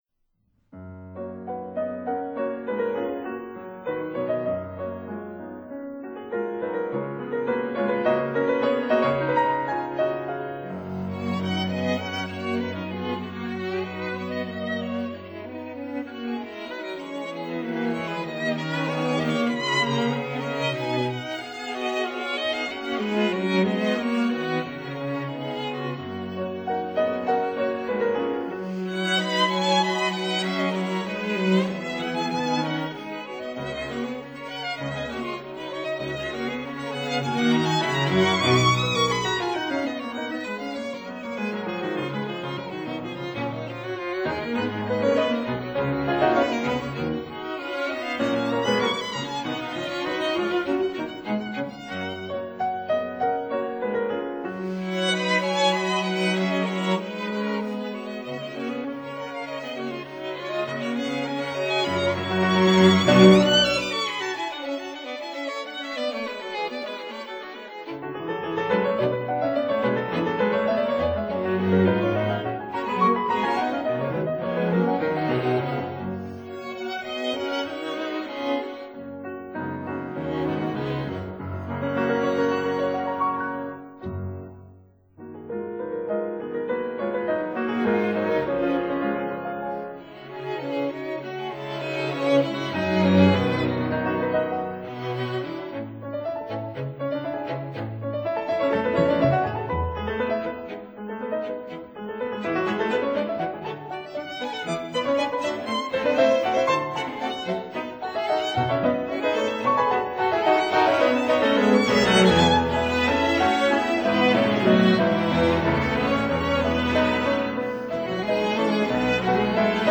violins
viola
cello
piano